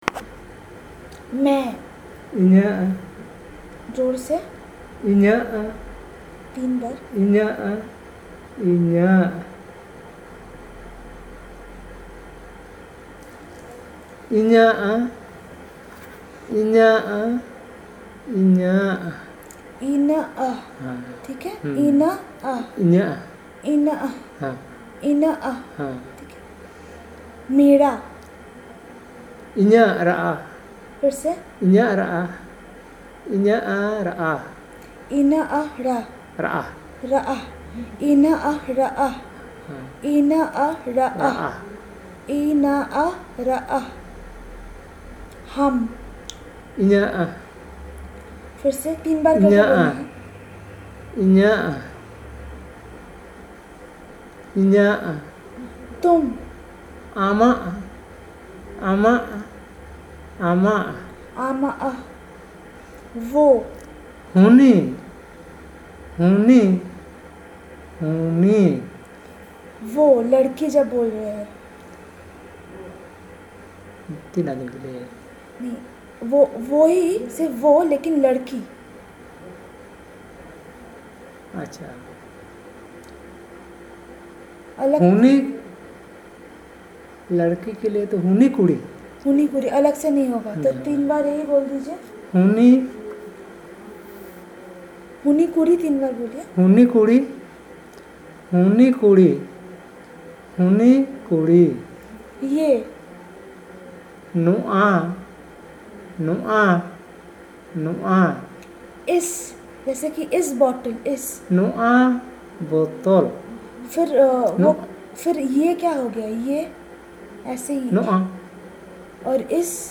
NotesThis is an elicitation of words for deictic terms like Pronouns and Demonstratives using Hindi as the language of input from the researcher's side, which the informant then translates to the language of interest